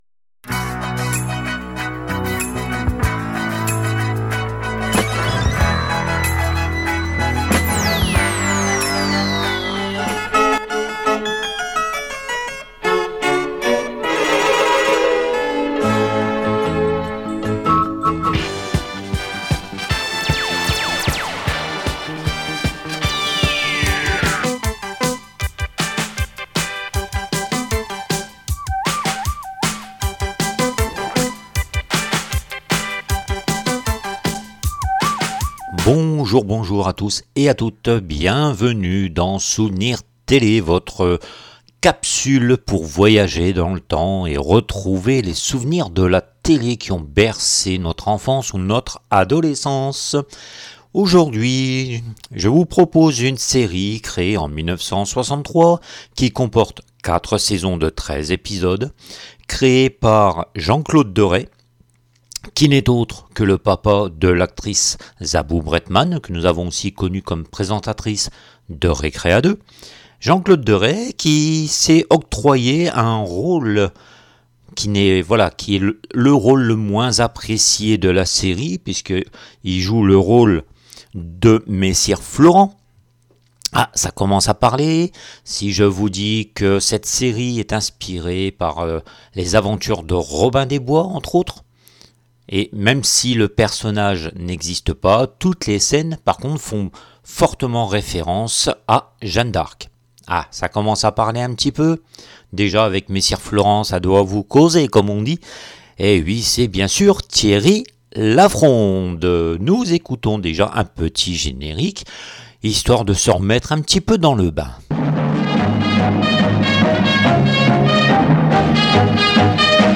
Plongez dans l’univers magique de votre enfance à travers des anecdotes étonnantes, des archives rares et des bandes-son originales qui ont marqué des générations.